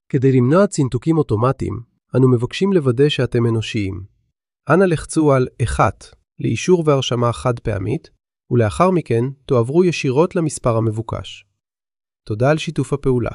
למי שרוצה אפשר להשתמש בהודעת הסבר הזאת: הודעת הסבר.wav